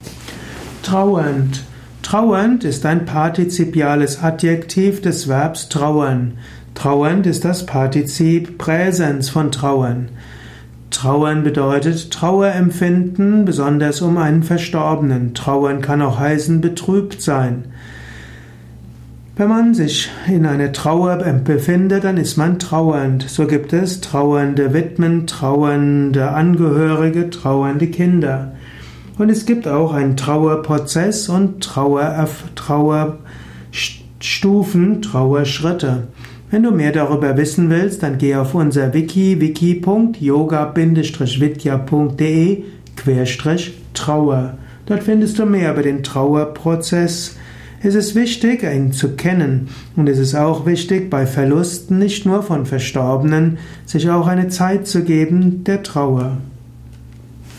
Dieser Audio Podcast über \" Trauernd \" ist die Tonspur eines Videos , zu finden im Youtube Kanal Persönlichkeit, Ethik und Umgang mit Schattenseiten .